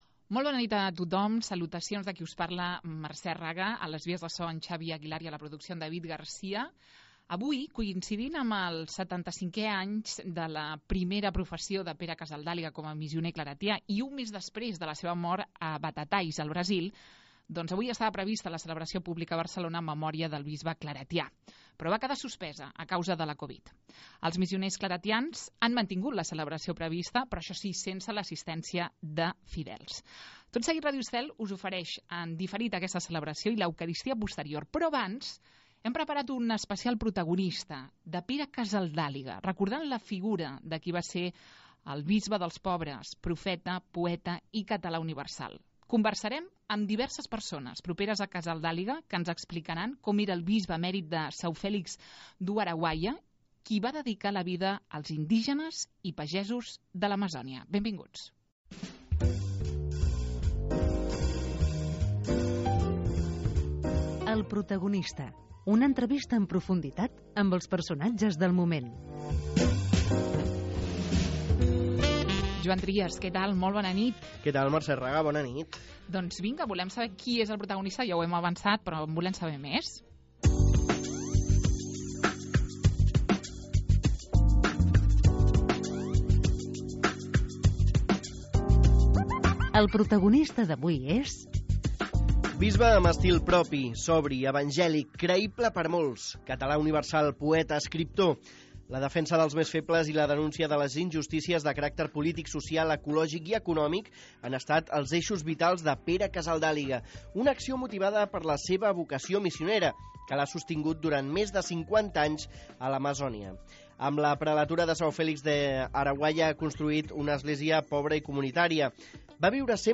Entrevista en profunditat a un personatge destacable del món de la política, societat, religió i cultura.